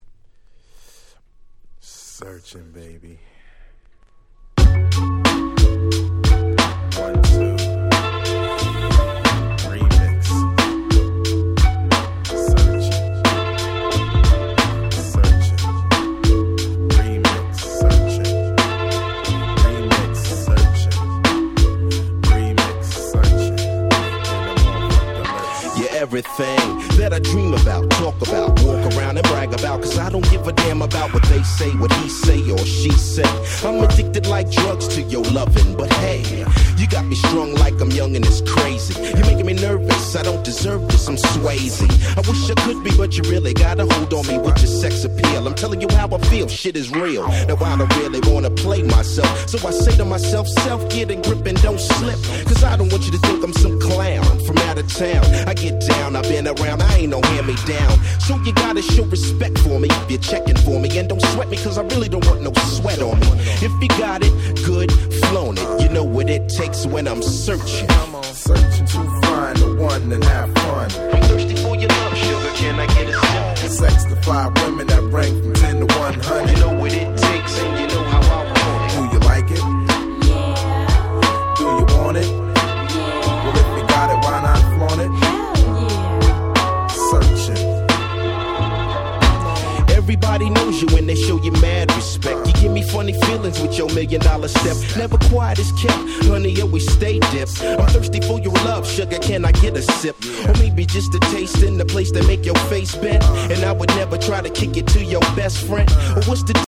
95' Super Nice Hip Hop !!